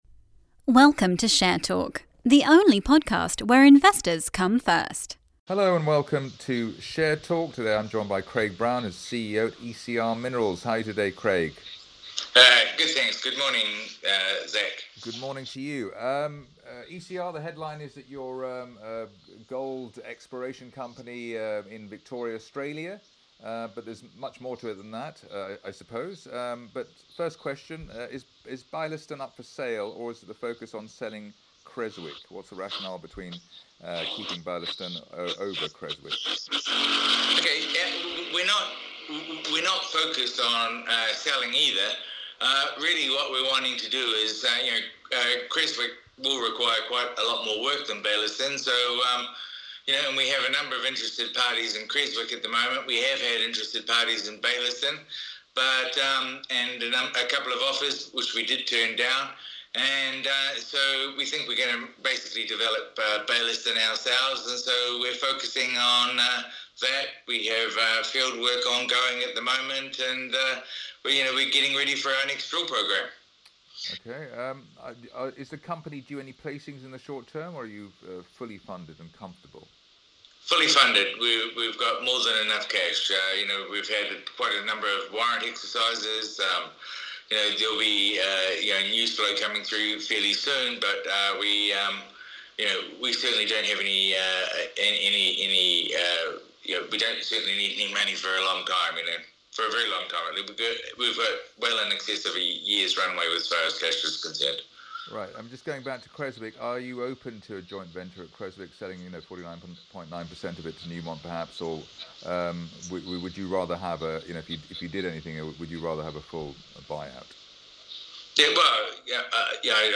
Q&A Podcast